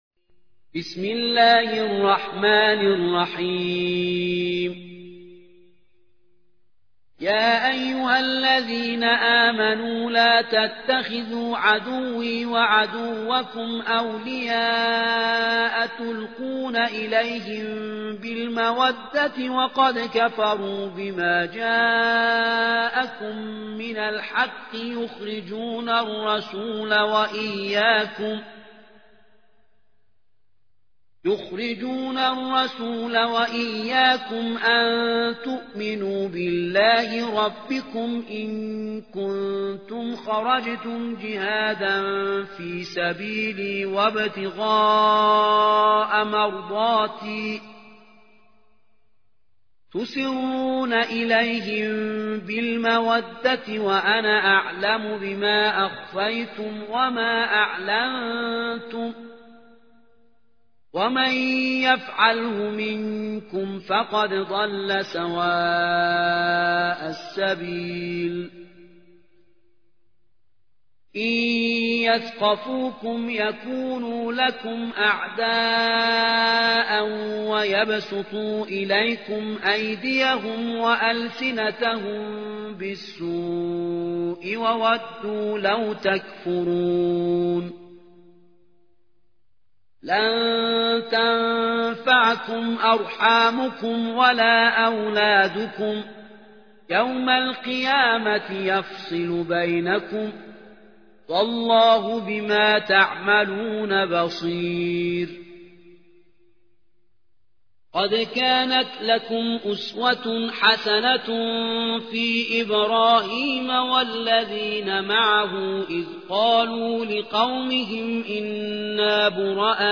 60. سورة الممتحنة / القارئ